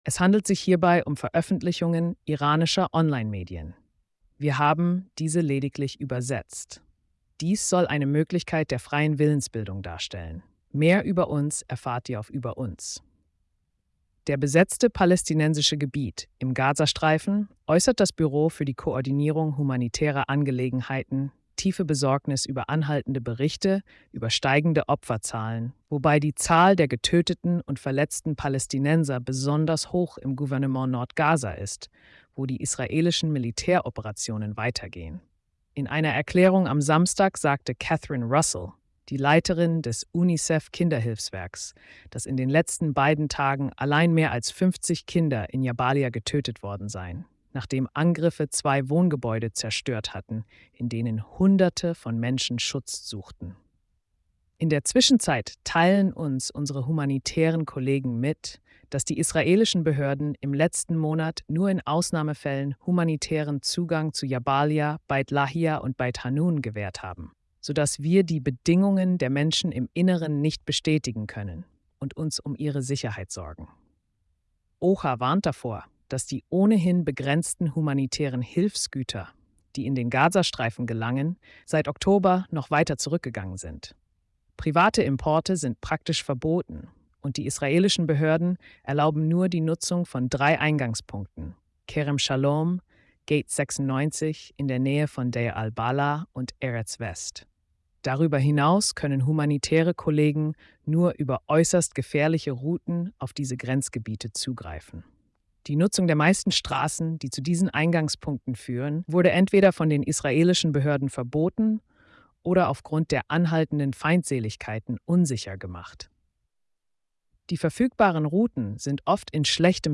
Besetztes Palästinensisches Gebiet, Ukraine, Libanon & mehr – Tägliche Pressekonferenz (4. Nov 2024)